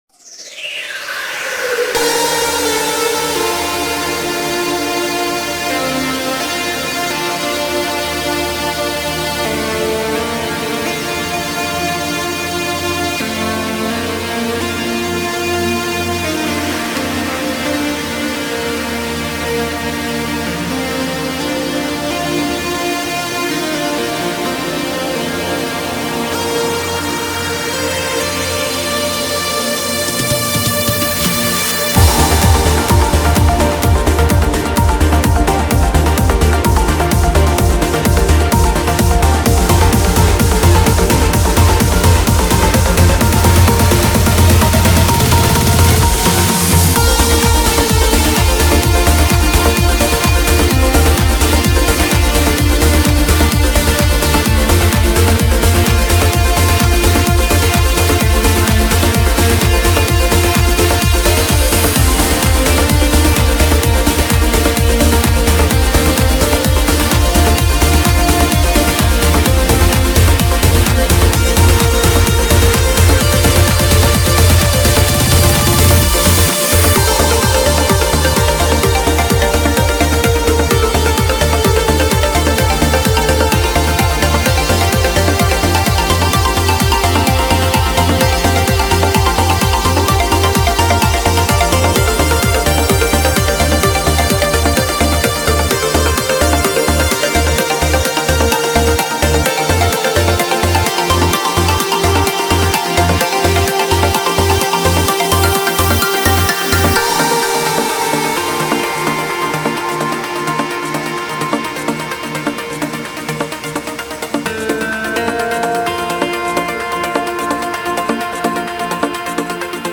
BPM128
Comments[UPLIFTING HAWAIIAN TRANCE]